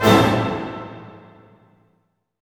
Index of /90_sSampleCDs/Roland L-CD702/VOL-1/HIT_Dynamic Orch/HIT_Orch Hit min
HIT ORCHM0CL.wav